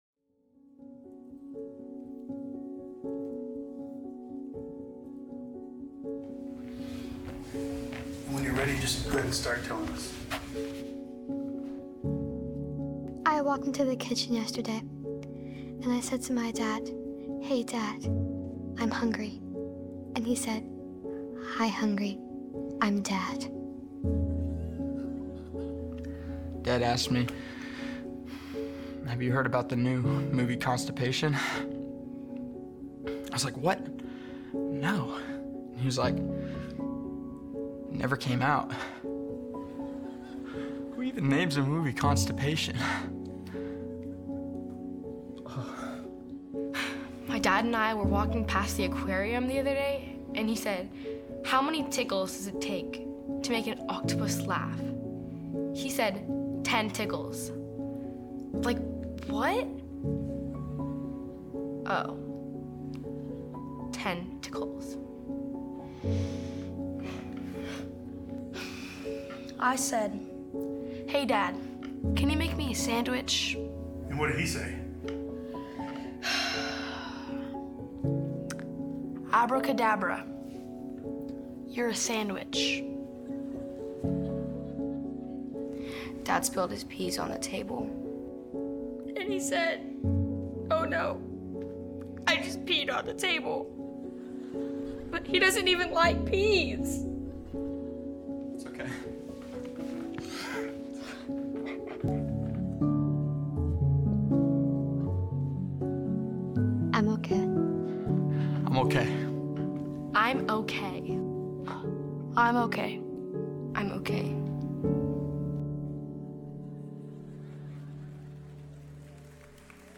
Faithbridge Sermons Jesus as Conqueror Jun 15 2025 | 00:38:22 1x 00:00 / 00:38:22 Subscribe Share Apple Podcasts Spotify Overcast RSS Feed Share Link Embed